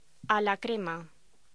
Locución: A la crema
voz
locución